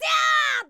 File:Young Link voice sample.oga
Young_Link_voice_sample.oga.mp3